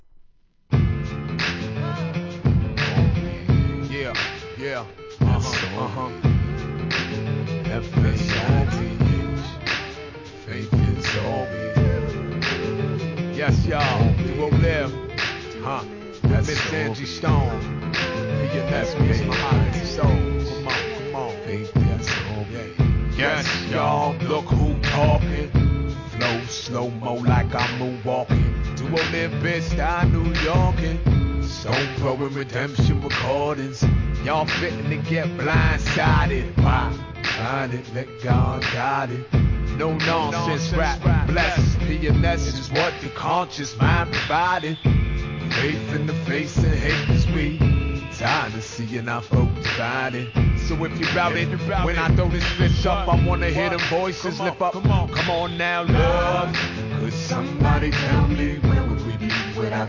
HIP HOP/R&B
コンシャスHIP HOP